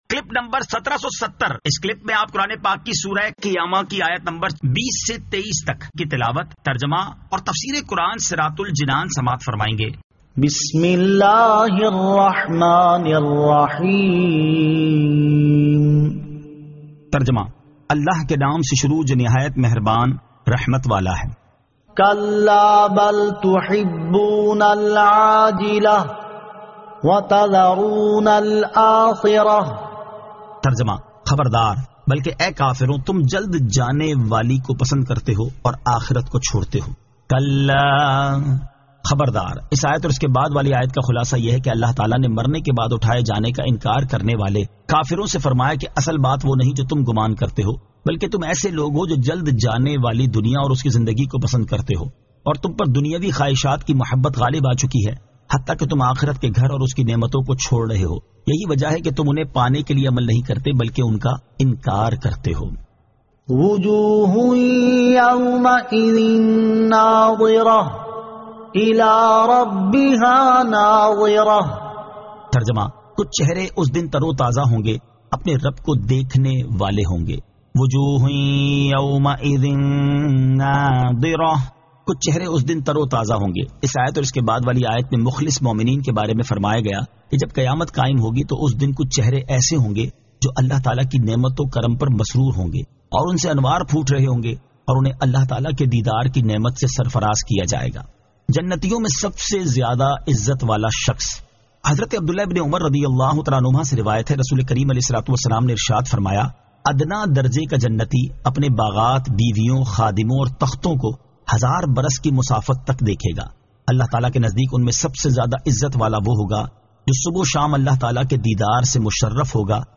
Surah Al-Qiyamah 20 To 23 Tilawat , Tarjama , Tafseer